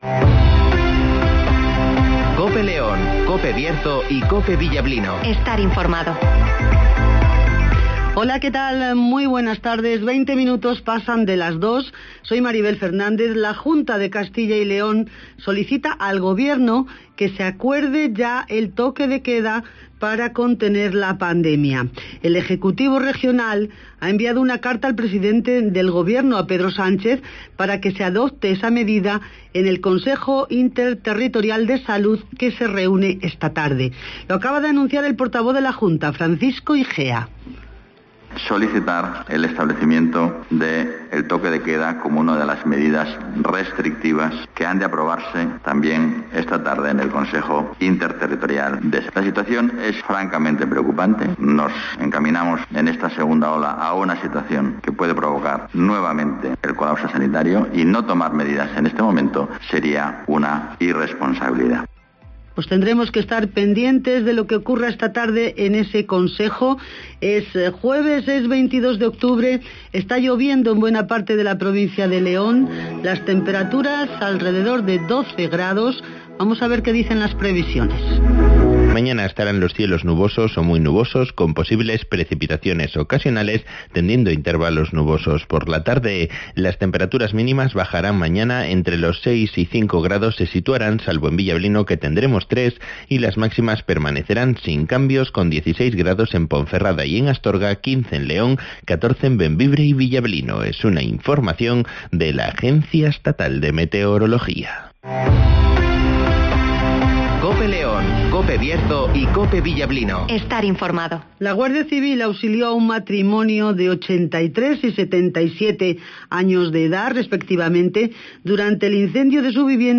INFORMATIVOS
Repaso a la actualidad informativa de la provincia de León. Escucha aquí las noticias con las voces de los protagonistas.
-Crisis del coronavirus (Palabras de Francisco Igea, portavoz de la Juna de Castilla y León)